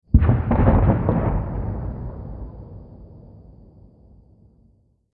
دانلود آهنگ نبرد 8 از افکت صوتی انسان و موجودات زنده
جلوه های صوتی